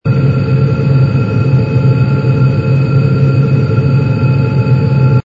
engine_ku_cruise_loop.wav